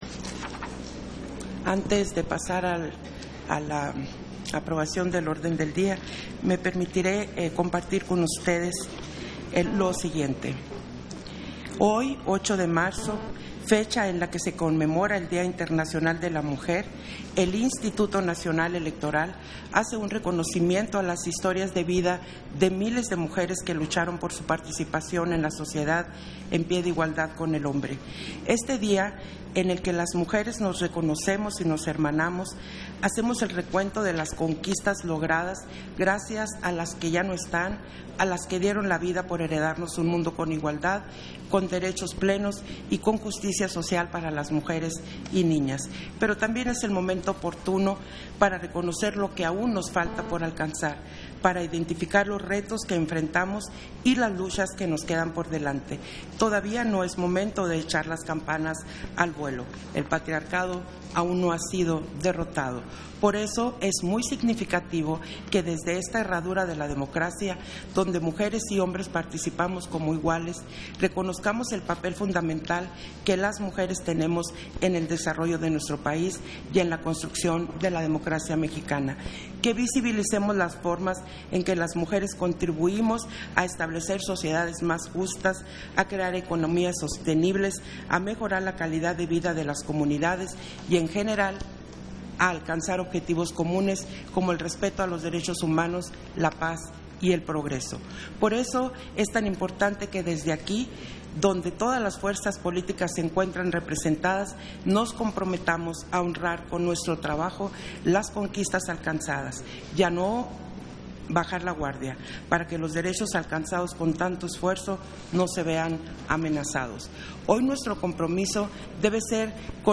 Audio del pronunciamiento de la Consejera Presidenta, Guadalupe Taddei
Pronunciamiento de la Consejera Presidenta, Guadalupe Taddei, al inicio de la Sesión Extraordinaria del Consejo General